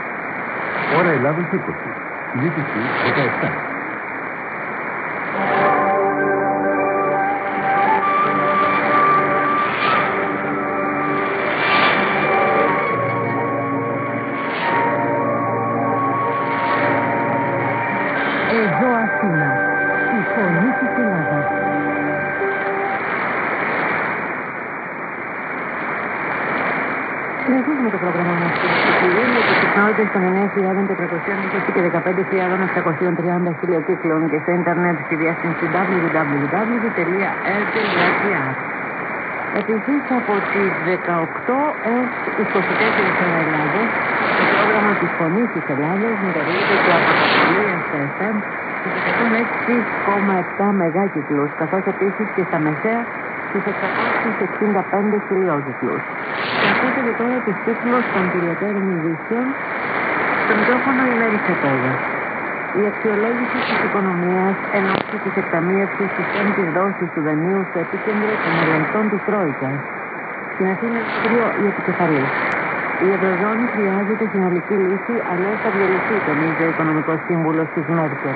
IS: interval signal
ID: identification announcement